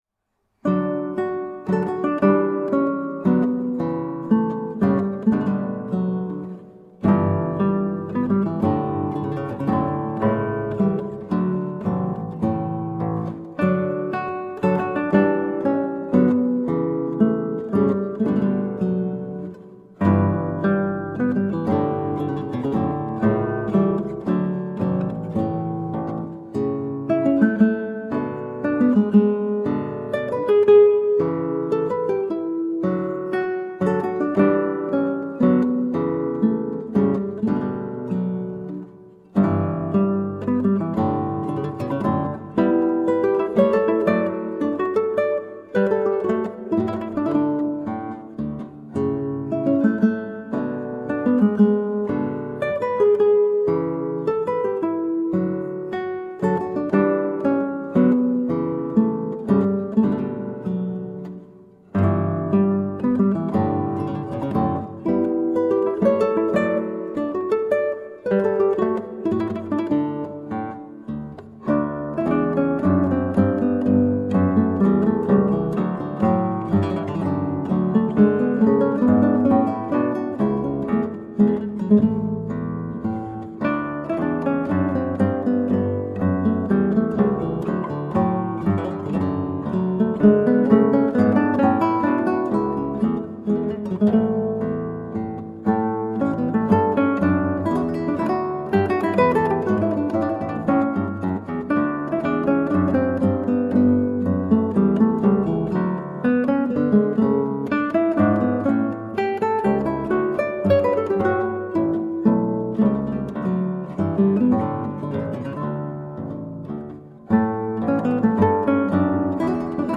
Colorful classical guitar.
Classical Guitar